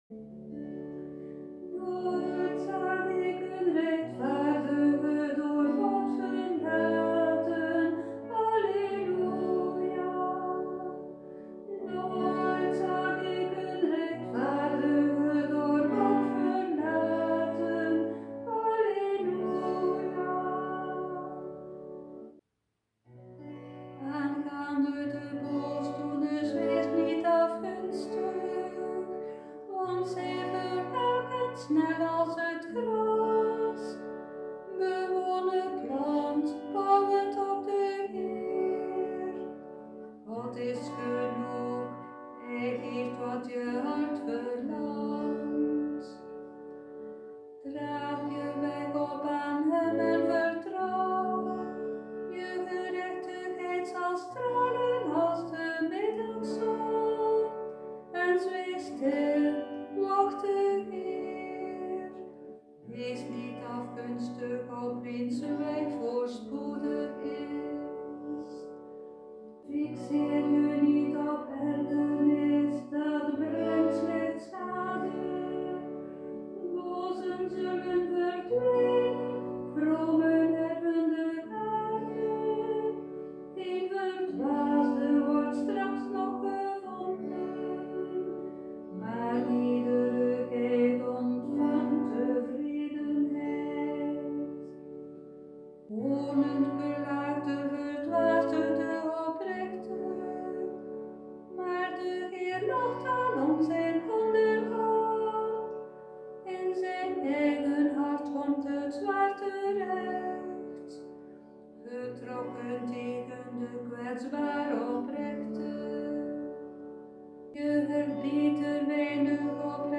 met citerbegeleiding